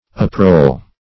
uproll - definition of uproll - synonyms, pronunciation, spelling from Free Dictionary Search Result for " uproll" : The Collaborative International Dictionary of English v.0.48: Uproll \Up*roll"\, v. t. To roll up.
uproll.mp3